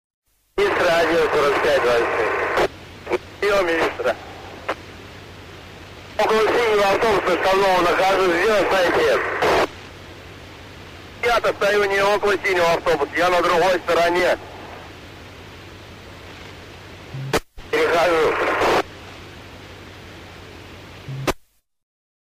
Звуки разговоров по рации
На этой странице собраны качественные звуки разговоров по рации – от четких переговоров до зашифрованных сообщений с фоновыми помехами.